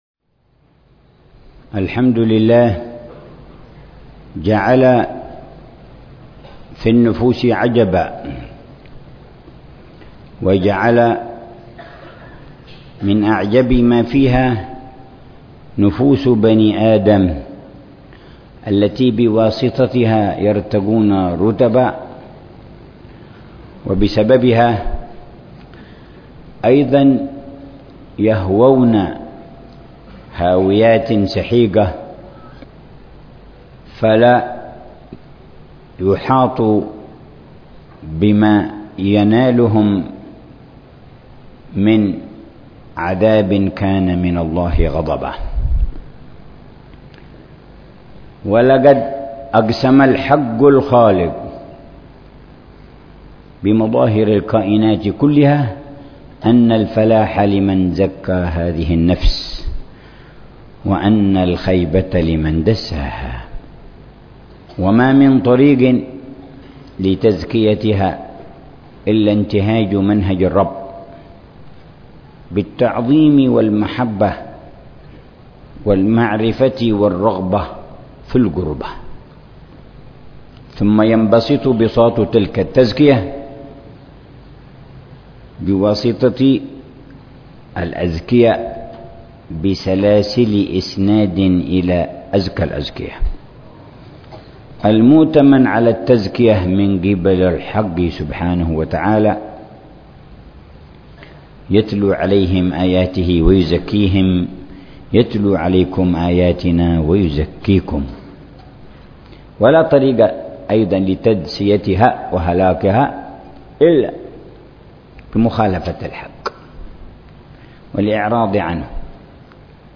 يواصل الحبيب عمر بن حفيظ تفسير قصار السور، موضحا معاني الكلمات ومدلولاتها والدروس المستفادة من الآيات الكريمة، ضمن دروسه الرمضانية في تفسير جز